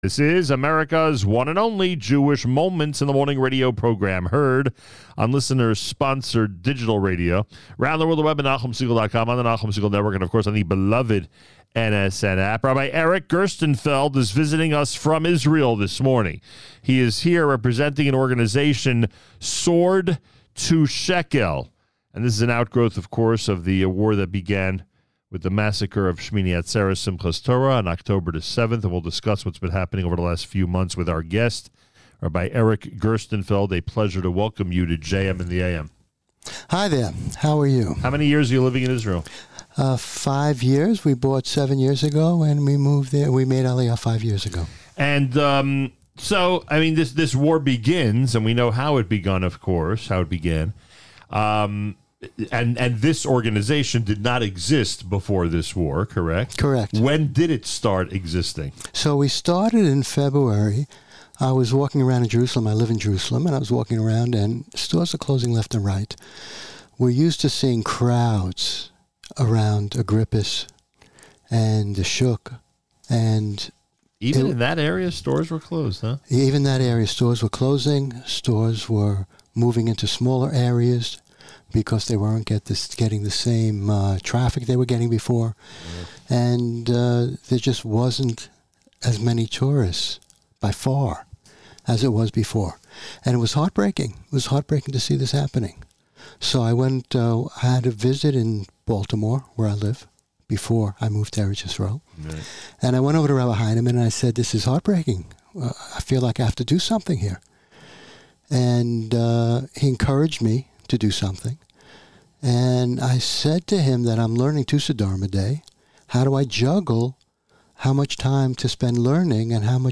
to the studio during this morning’s JM in the AM